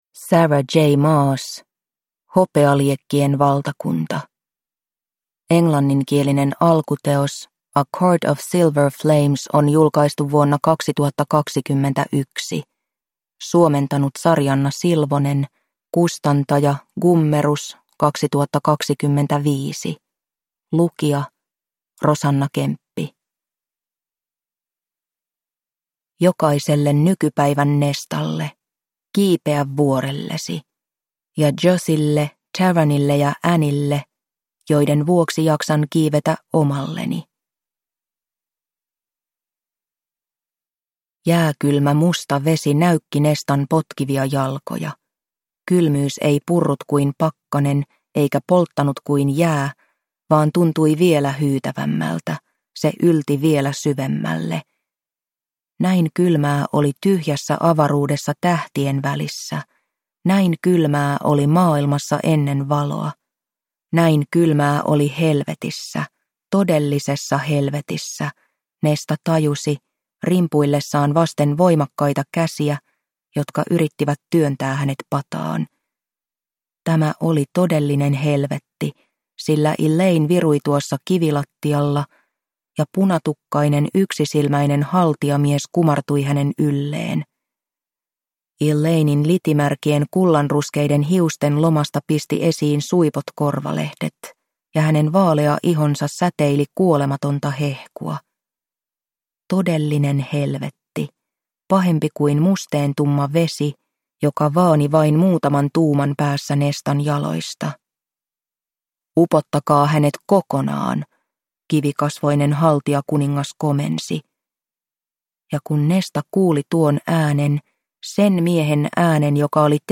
Hopealiekkien valtakunta – Ljudbok